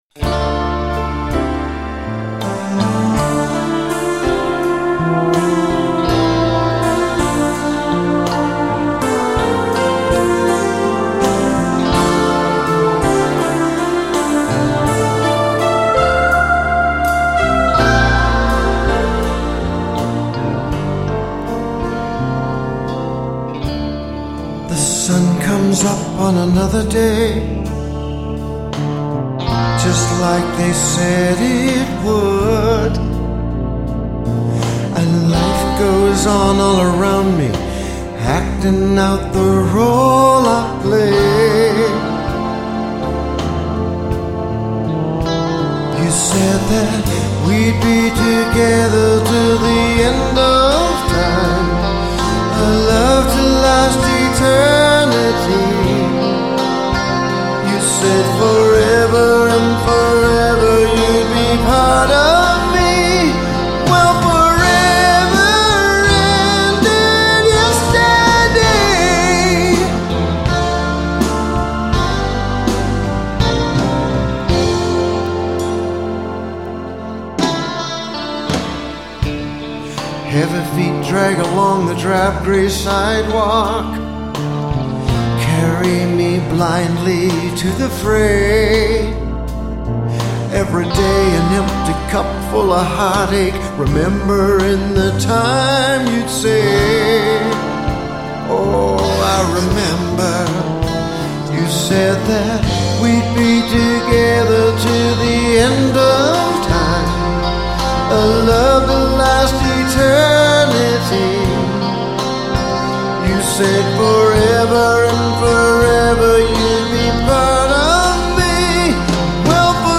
About promises made and broken.  The chorus has a chordal turn around in it which is an homage to Paul McCartney and “Yesterday”..the whispered “remember” is a reference to “Walking in the Sand”